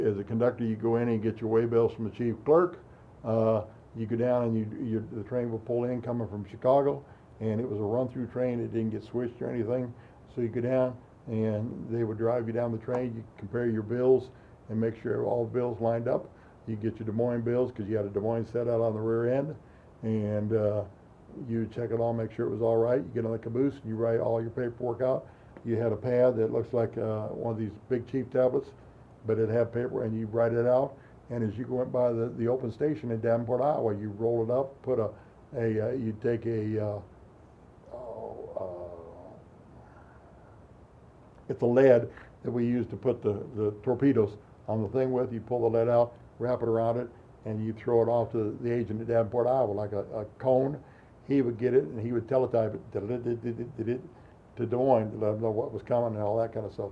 Interview Clip